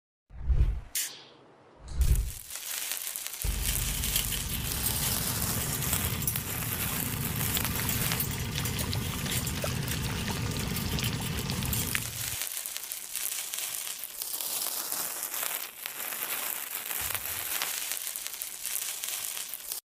Metal Sponge Melts Close Up Sound Effects Free Download